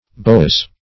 Boa \Bo"a\ (b[=o]"[.a]), n.; pl. Boas.